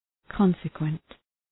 Προφορά
{‘kɒnsə,kwent}